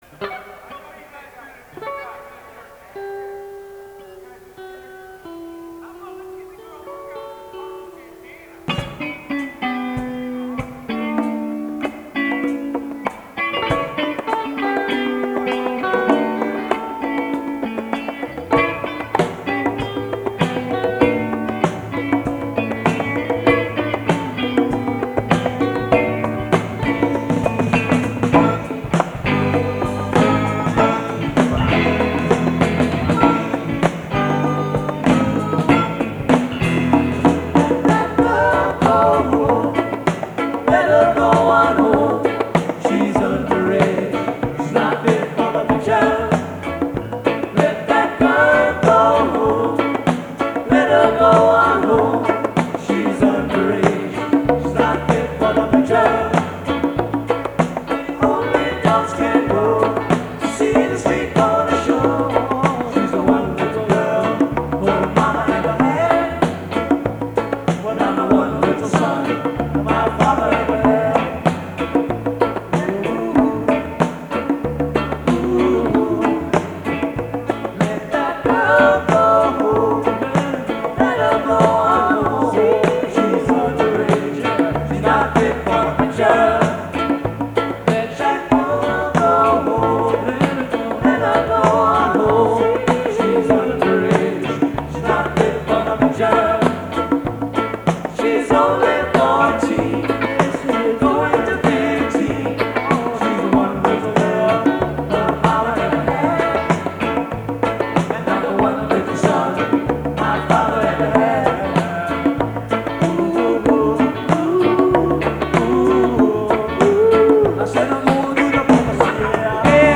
Live Recordings
Norwalk, CT